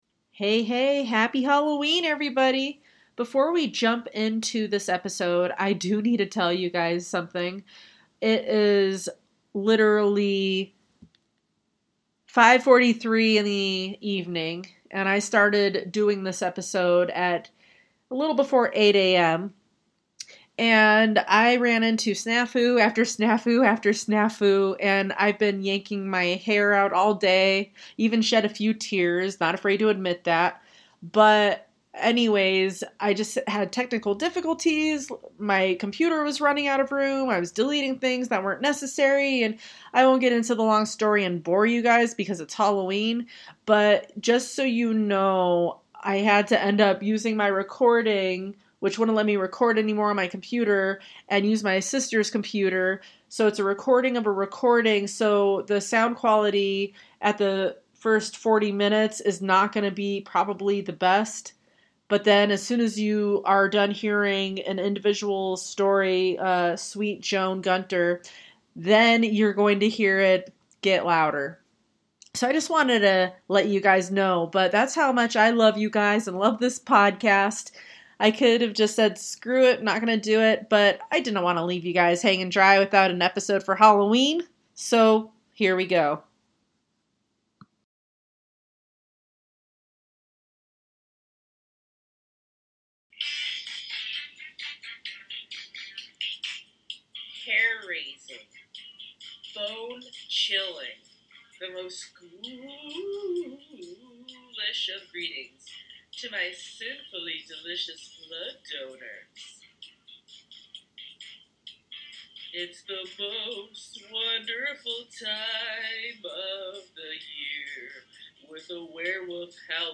I started around early and went on till dark, 13 hours... so when you listen please have patience if the sounding isn't completely normal and just know it's because I truly love this podcast and have fun doing it that I didn't give up!